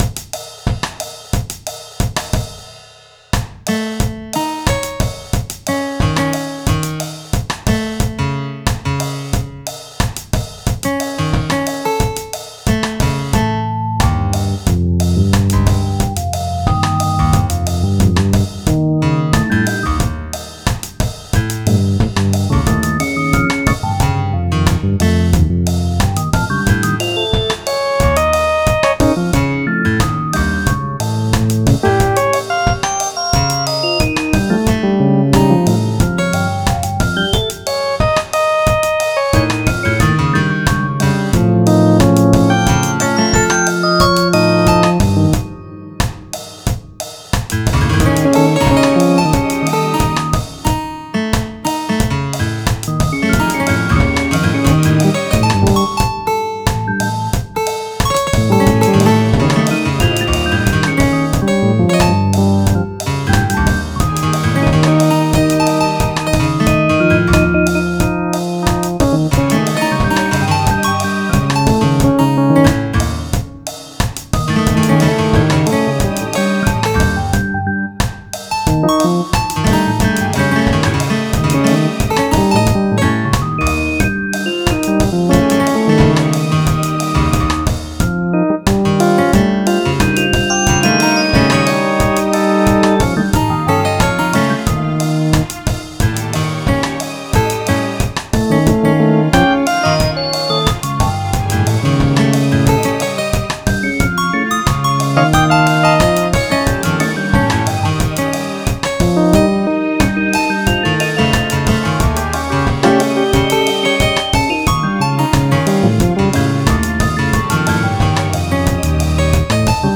Categorised in: light-hearted, rhythmic